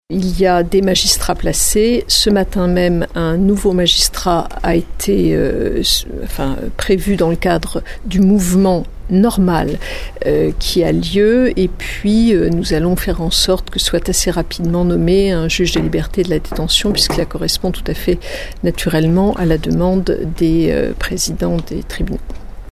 On écoute Nicole Belloubet :